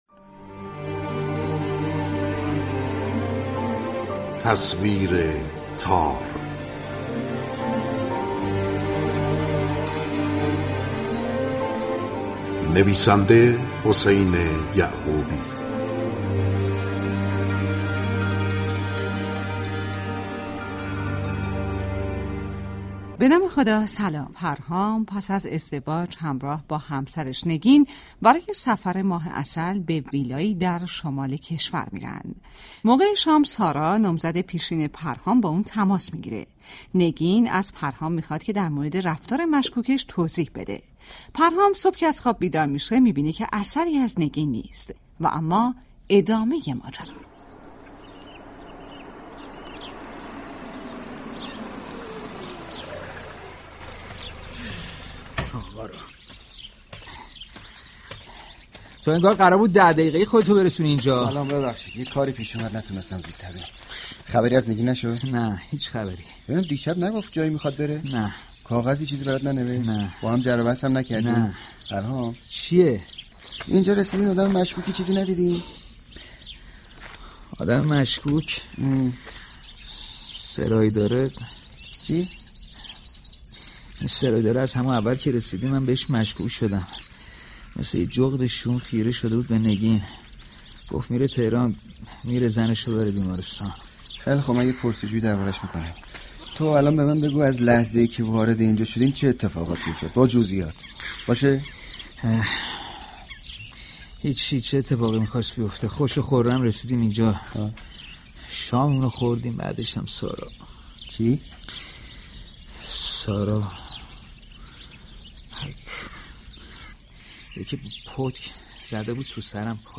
به گزارش روابط عمومی اداره كل هنرهای نمایشی رادیو ، نویسندگی این نمایش رادیویی را حسین یعقوبی بر عهده دارد و داستان آن درباره زن و شوهری است كه برای ماه عسل به ویلایی در شمال می روند اما آنجا دچار بحران شده و.....
سریال رادیویی